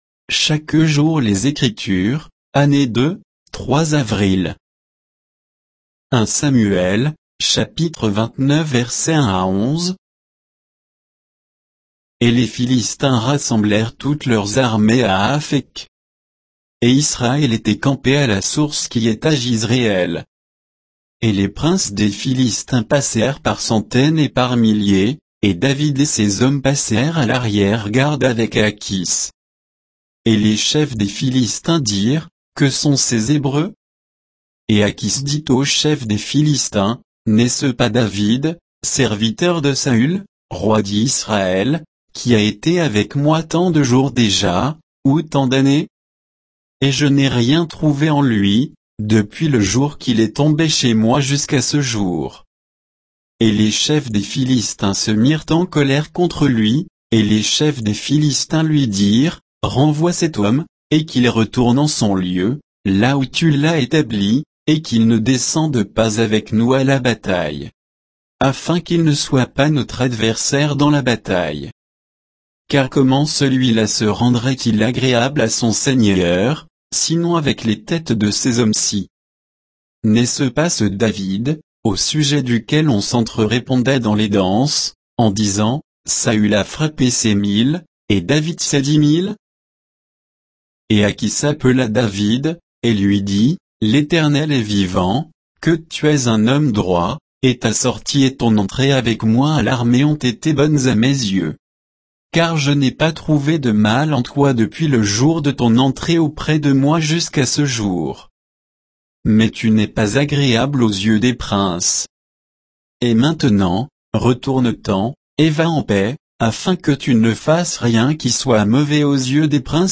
Méditation quoditienne de Chaque jour les Écritures sur 1 Samuel 29